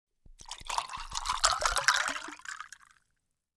دانلود آهنگ آب 1 از افکت صوتی طبیعت و محیط
جلوه های صوتی
دانلود صدای آب 1 از ساعد نیوز با لینک مستقیم و کیفیت بالا